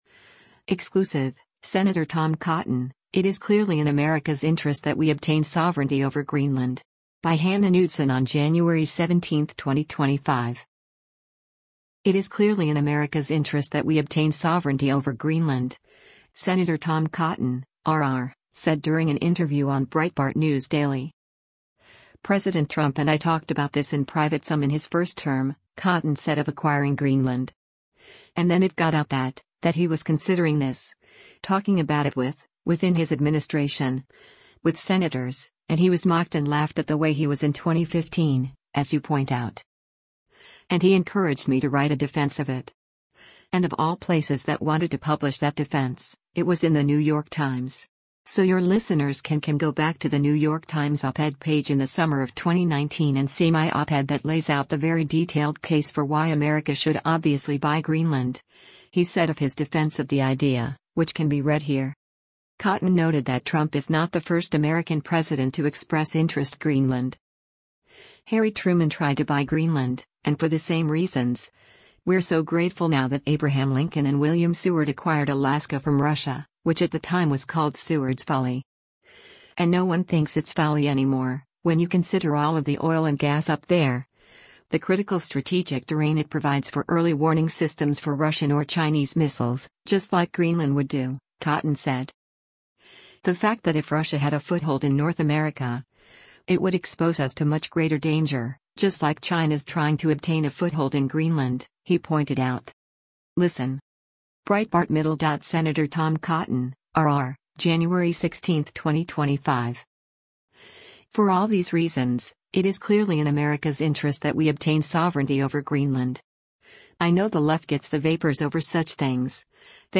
It is “clearly in America’s interest that we obtain sovereignty over Greenland,” Sen Tom Cotton (R-AR) said during an interview on Breitbart News Daily.
Breitbart News Daily airs on SiriusXM Patriot 125 from 6:00 a.m. to 9:00 a.m. Eastern.